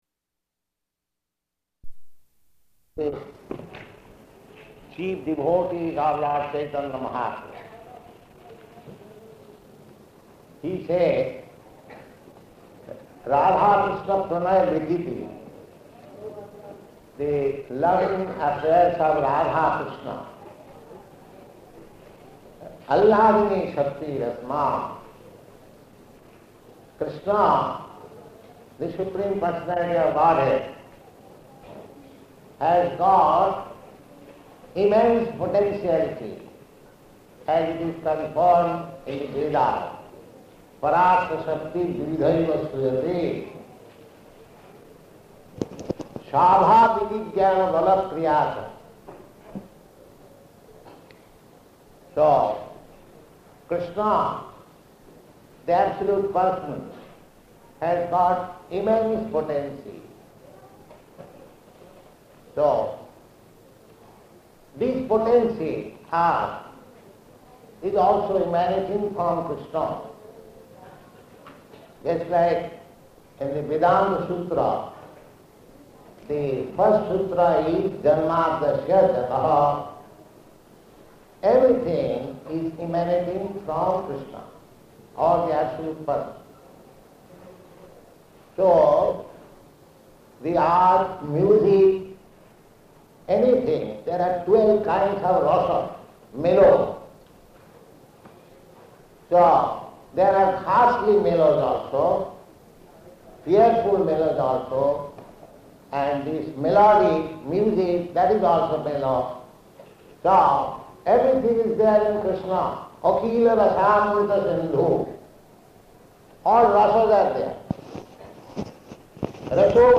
Type: Lectures and Addresses
Location: Bombay